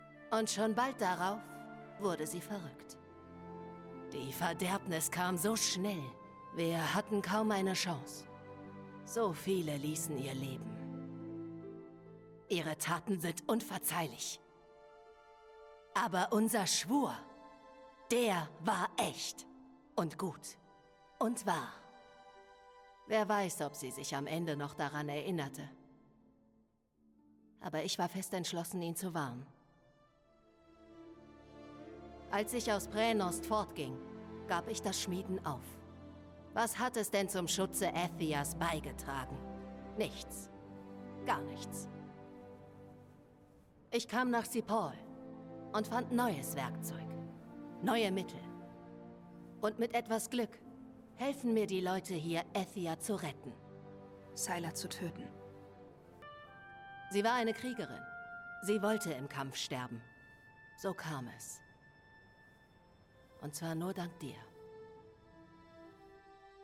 Forspoken (Johedy) – wissend/stark